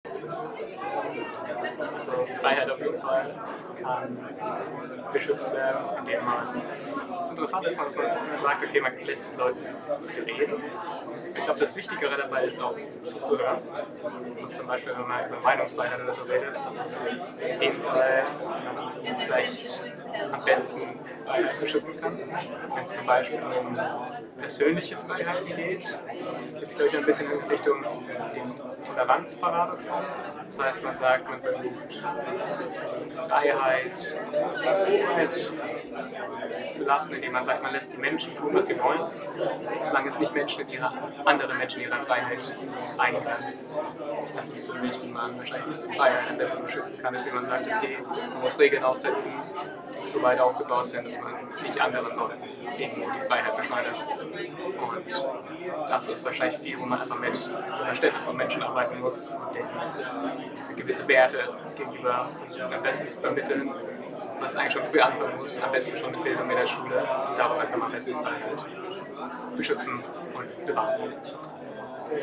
Standort der Erzählbox:
FALLING WALLS 2024 @ Falling Walls Science House, Berlin